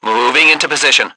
1 channel
H_soldier3_33.wav